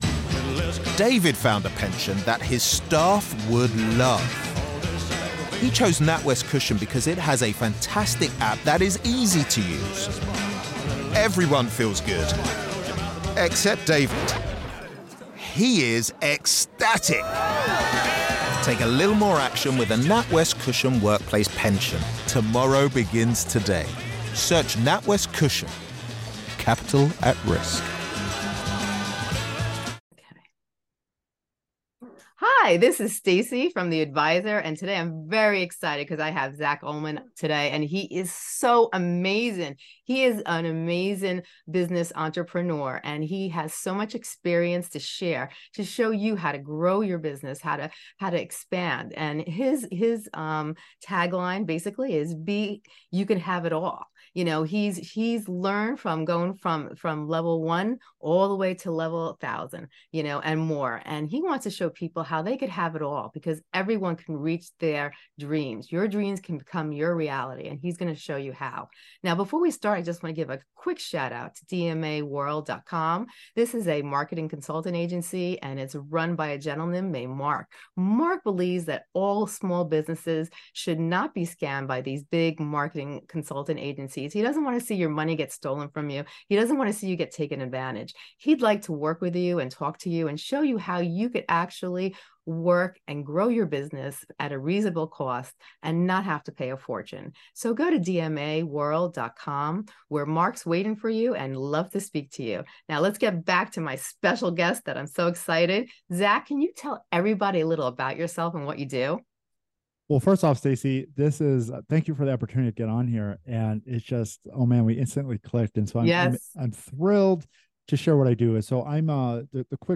The conversation ended with plans for future episodes to help overcome obstacles and turn dreams into reality. The discussion emphasized the power of learning and having guidance to reach one's goals.